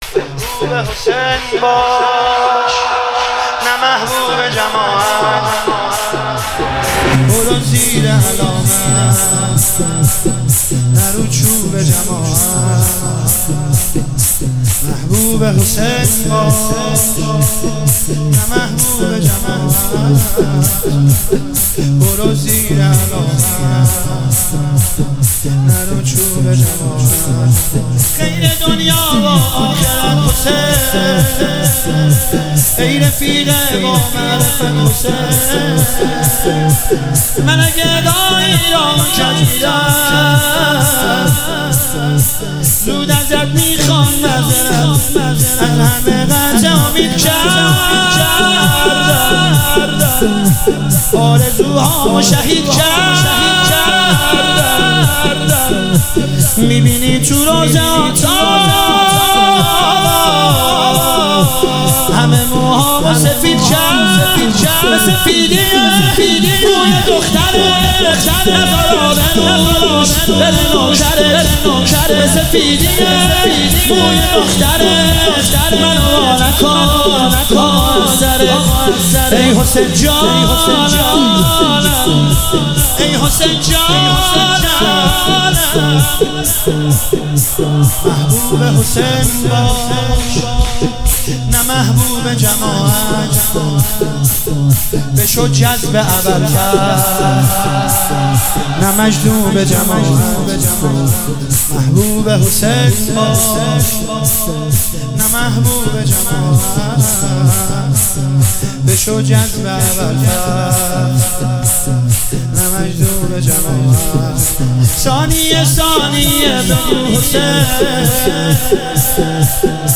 سالروز تخریب قبور ائمه بقیع 1402